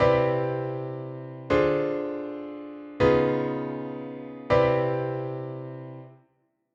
We used the same blue and red notes and made a Db7.
This unique sound adds a cool harmonic texture that can give interesting sounds from various genres including emd, lo-fi, jazz, hip hop, and more.